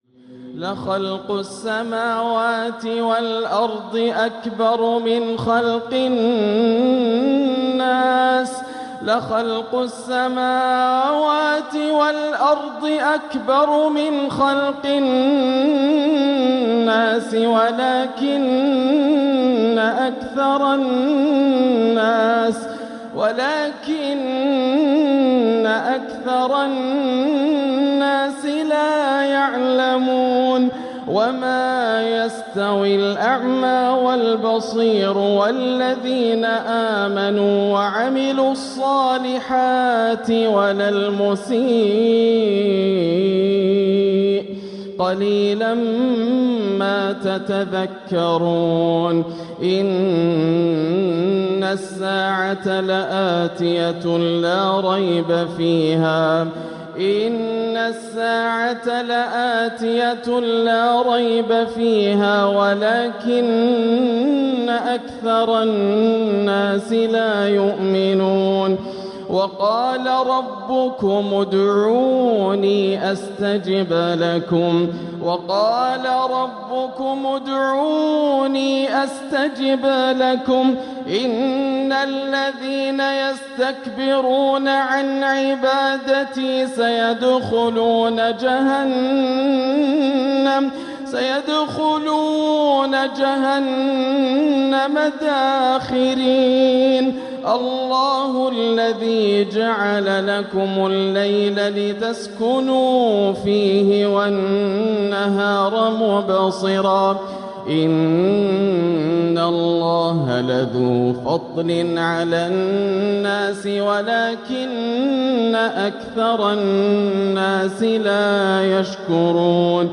تراتيل تملأ القلب! مختارات نديّة من تلاوات الشيخ ياسر الدوسري – [4–10 جمادى الأولى 1447هـ] > الإصدارات > المزيد - تلاوات ياسر الدوسري